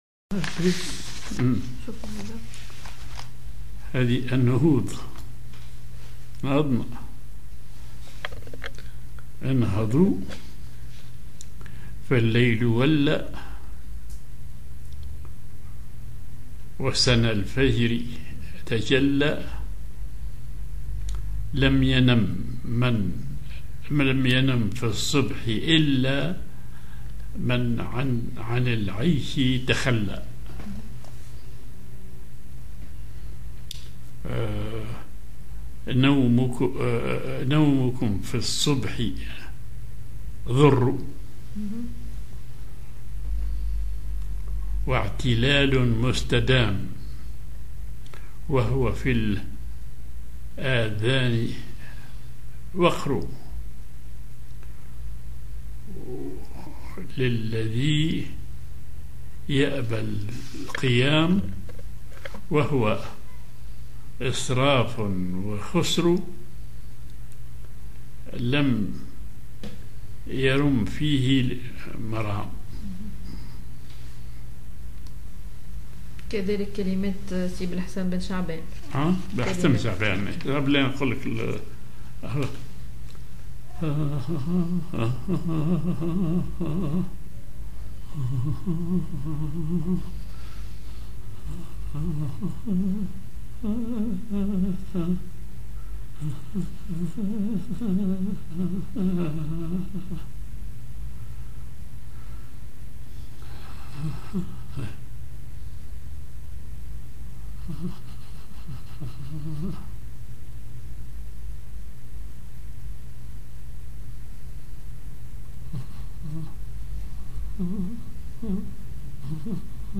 Maqam ar نهاوند
genre نشيد